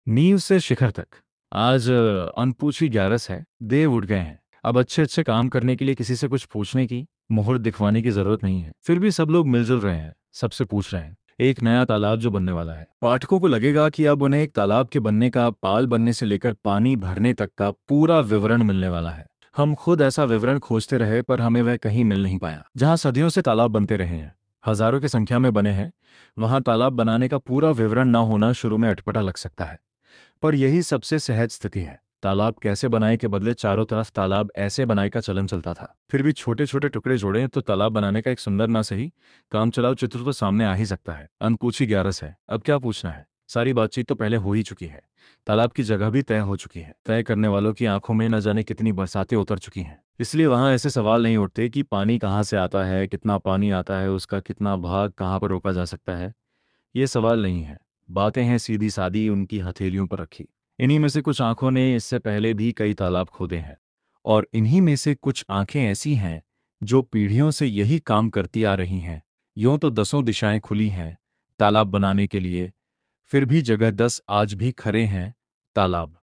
Yes it's speech to text audio is very natural.